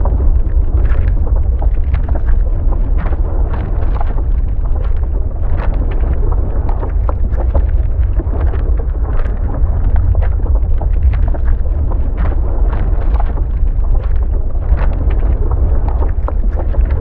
Sfx_creature_iceworm_move_ice_loop_01.ogg